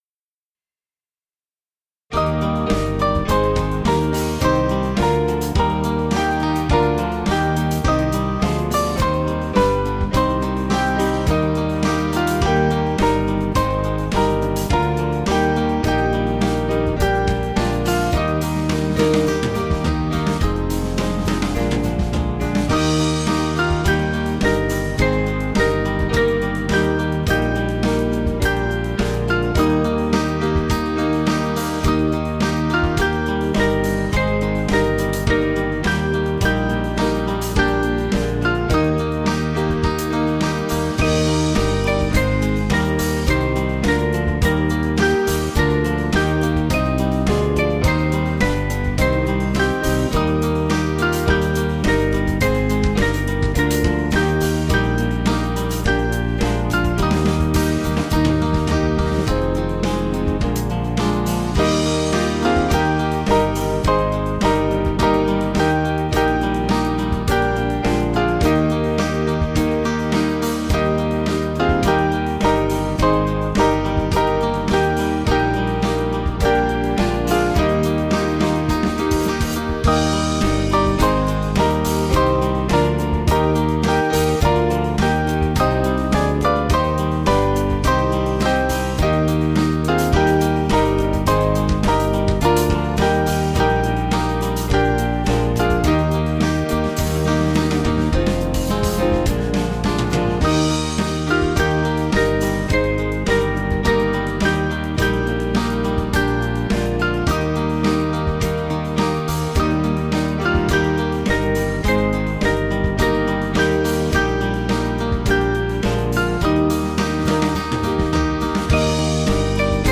Small Band
(CM)   4/Eb 490.8kb